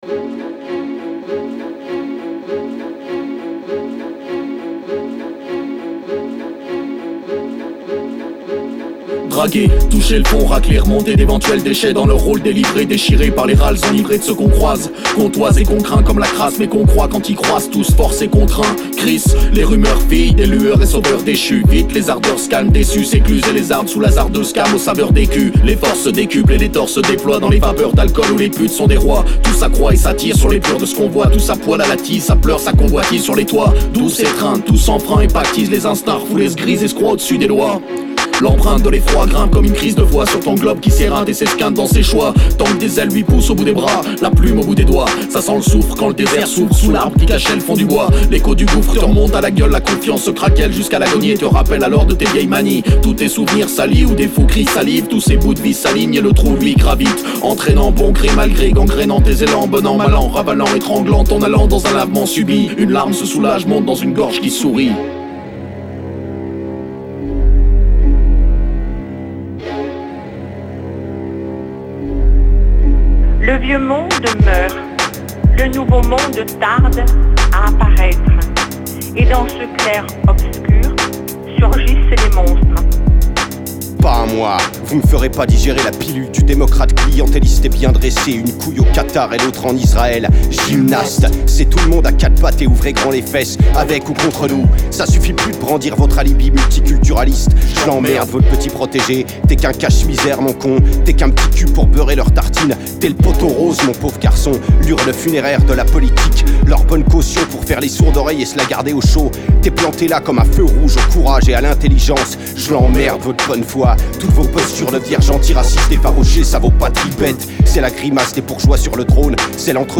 Du gros war hip hop qui gratte les chicots